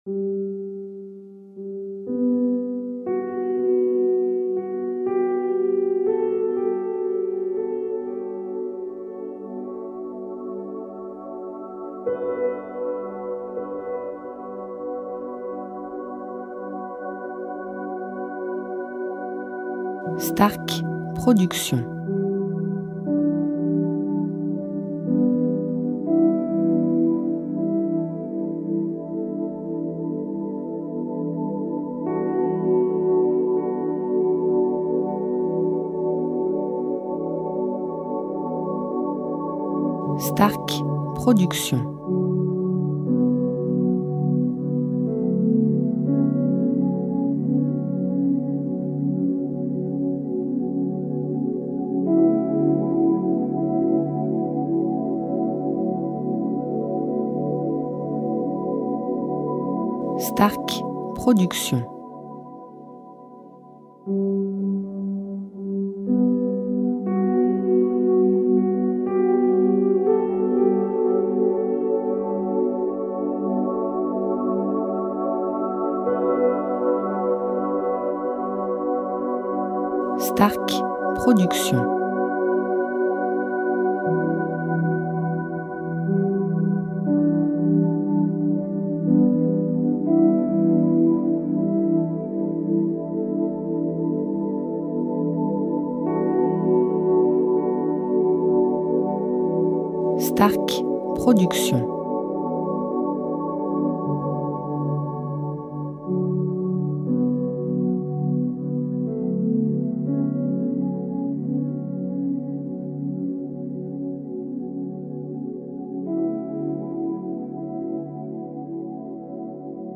style Sophrologie Méditation durée 1 heure